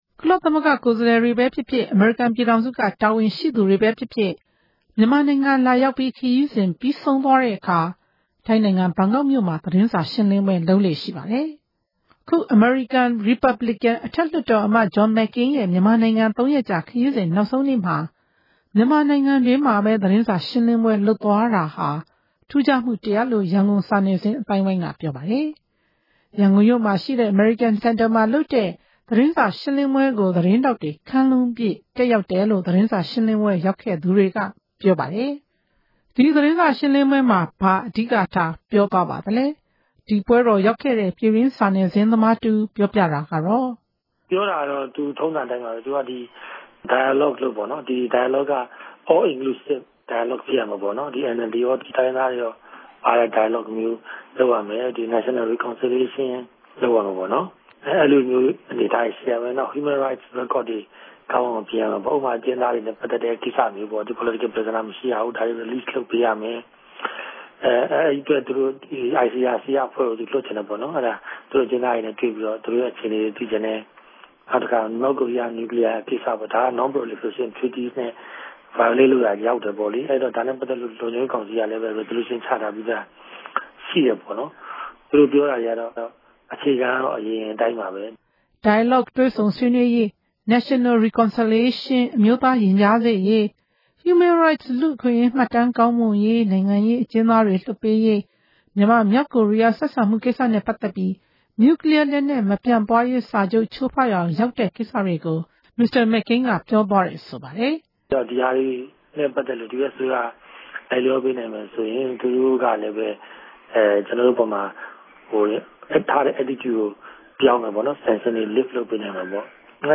စုစည်းတင်ပြချက်။